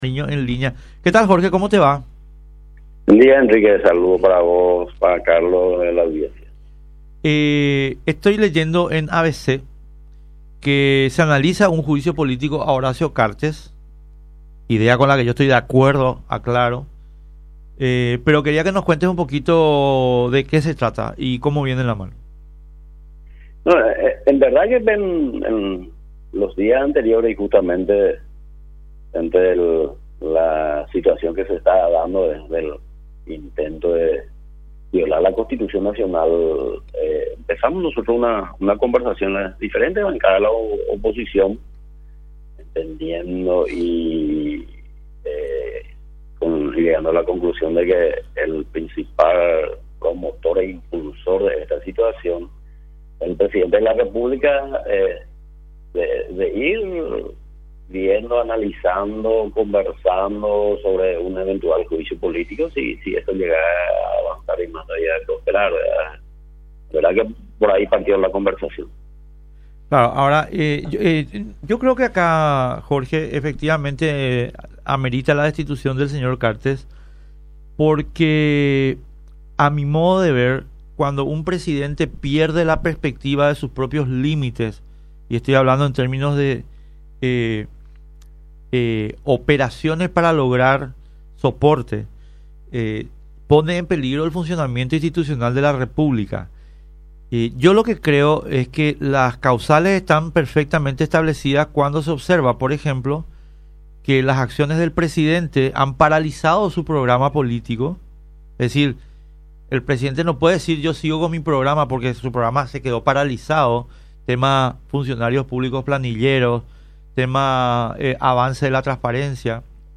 El Diputado Jorge Ávalos Mariño opinó en la 800 AM sobre posible juicio político al Presidente Horacio Cartes y manifestó que el principal propulsor de esta situación de violación a la Constitución Nacional es el Presidente.